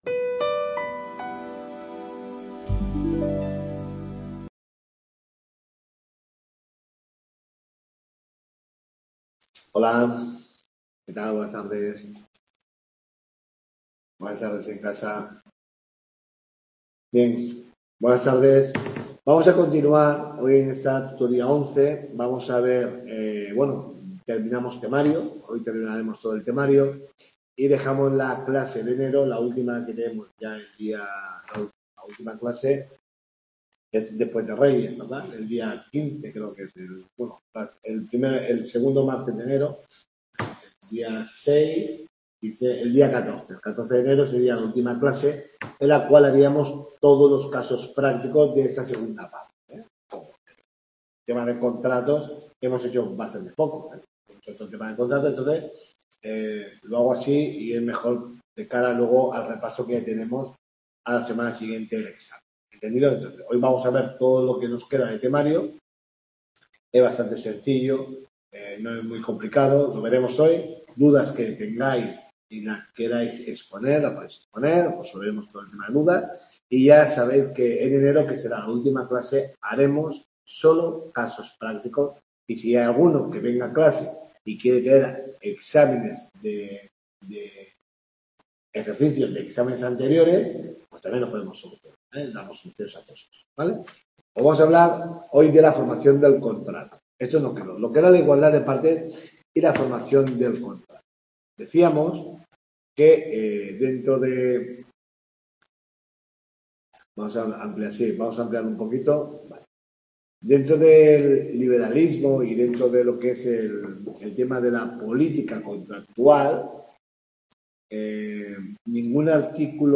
TUTORIA 11